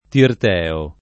Tirteo [ tirt $ o ]